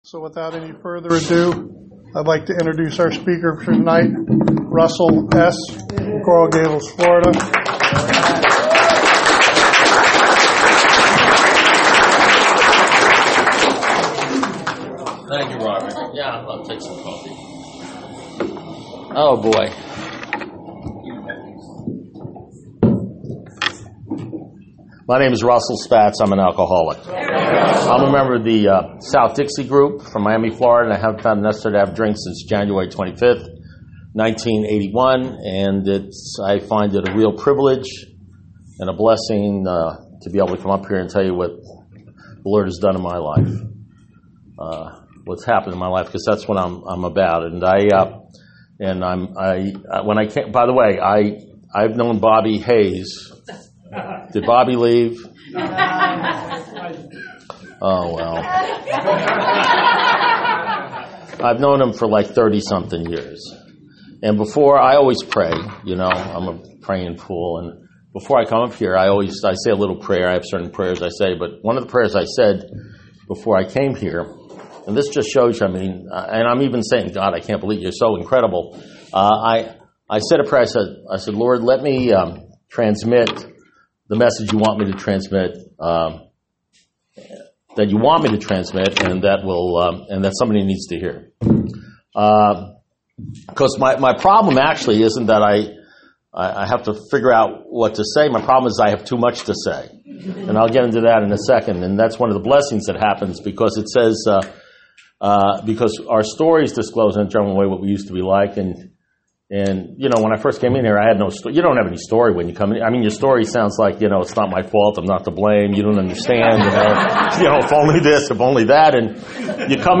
Alcoholics Anonymous Speaker Recordings